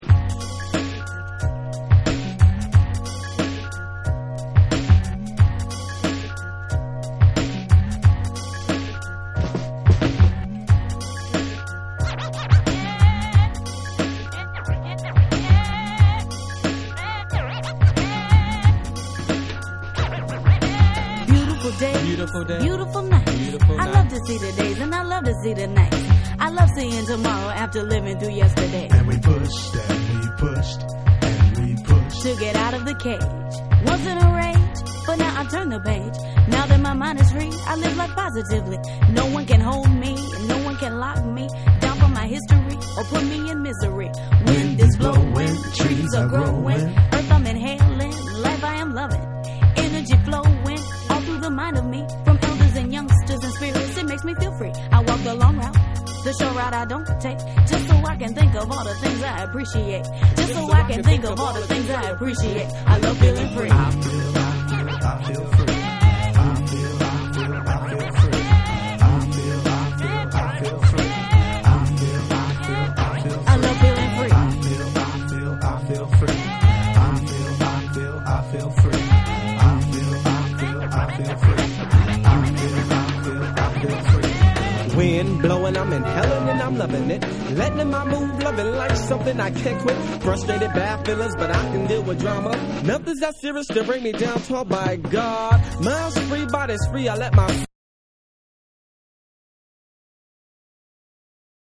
ドリーミーなトラック・メイクが心地よい3（SAMPLE1）は、生音DJにも人気の1曲。
男女混声のフロウも楽しいニュースクールの傑作アルバム
BREAKBEATS / HIP HOP